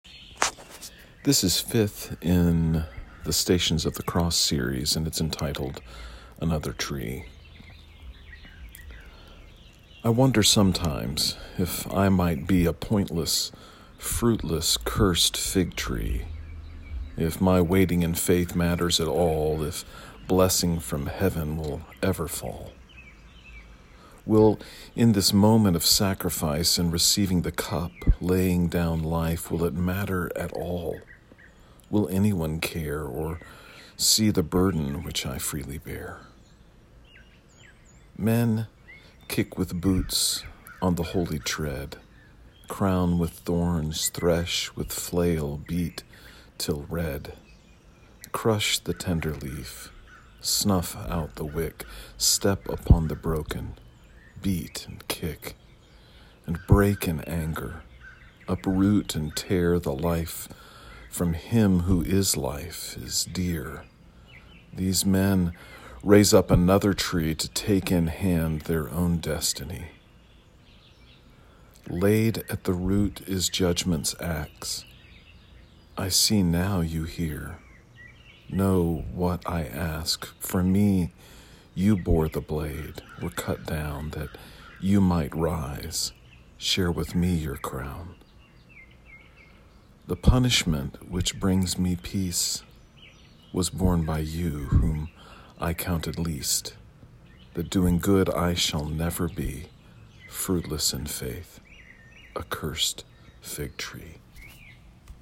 You may listen to me read the poem via the player below.